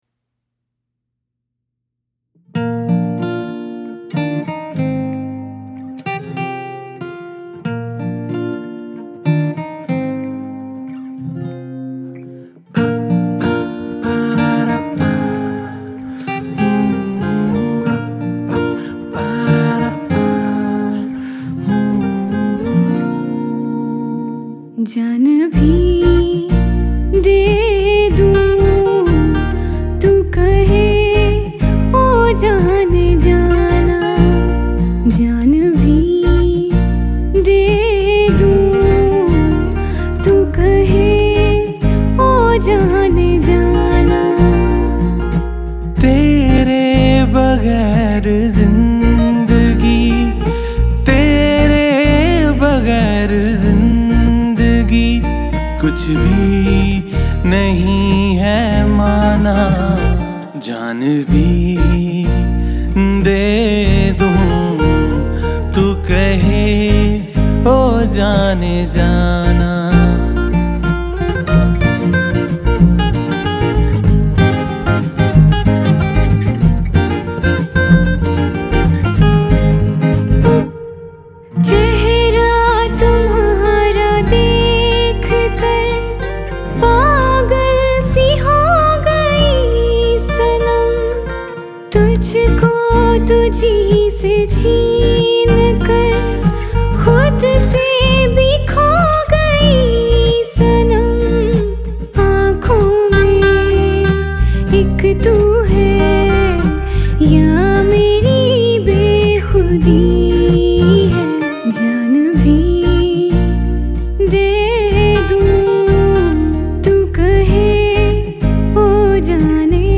That is really a soulful composition.
light tunes of the guitar
a perfect romantic song